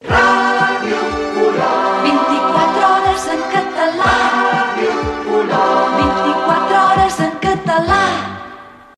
Indicatiu 24 hores en català